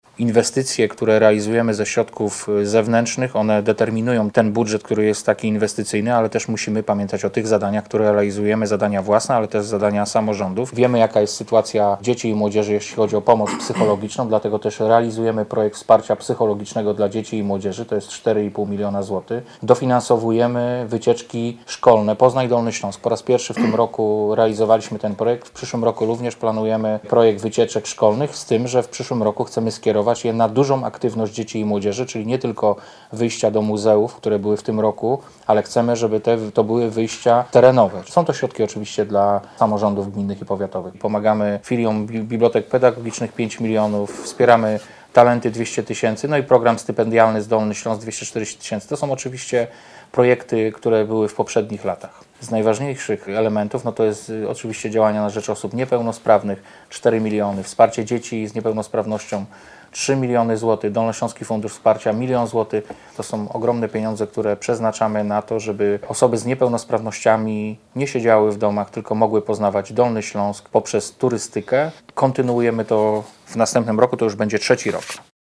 Podczas konferencji prezentującej założenia budżetowe członkowie zarządu województwa podkreślili, że przyszłoroczny budżet będzie większy od tegorocznego o 800 mln zł.
Samorząd kontynuuje także wsparcie i aktywizację osób z niepełnosprawnościami, zaznacza wicemarszałek województwa Wojciech Bochnak.